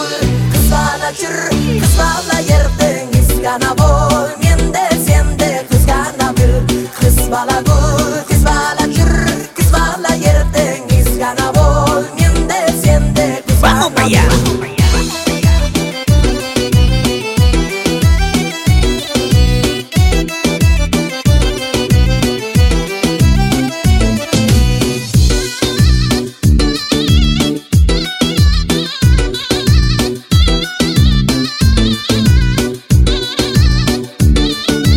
Жанр: Латиноамериканская музыка / Русские